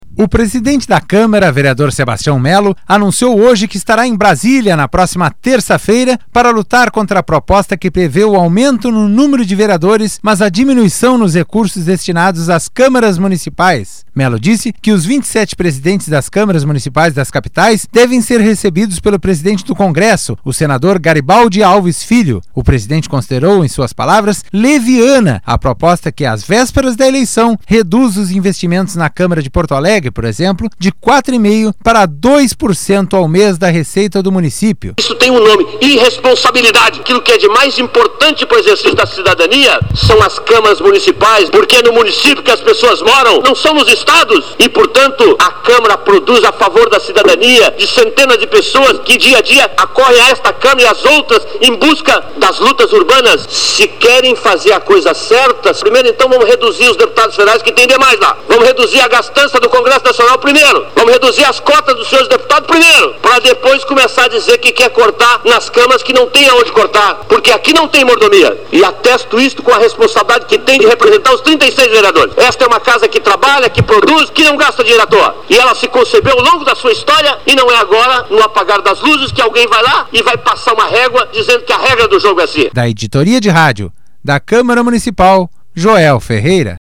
O presidente da Câmara Municipal de Porto Alegre, vereador Sebastião Melo (PMDB), criticou nesta quinta-feira (29/5), durante a sessão ordinária, proposta aprovada na noite de ontem (29/5), em segundo turno, na Câmara Federal, criando 471 cadeiras para as câmaras municipais do país.